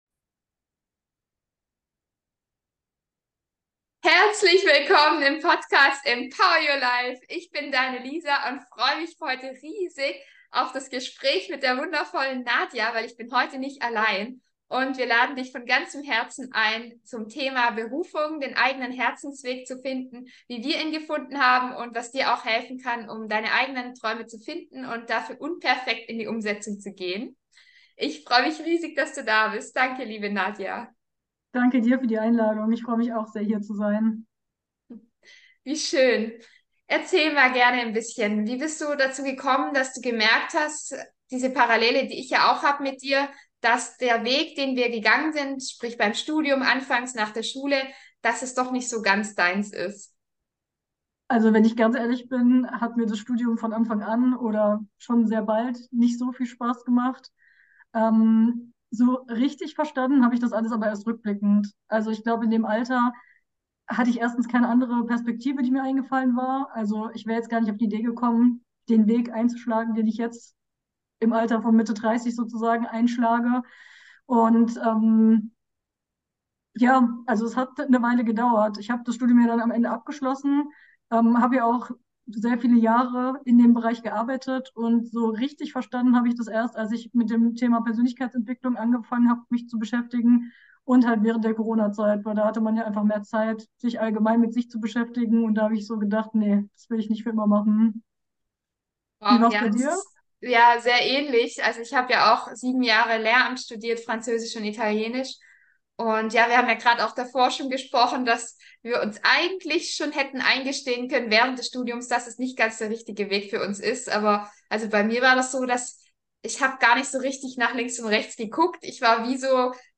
Finde deinen Sinn: Wie du deine Herzenswünsche verwirklichst -Interview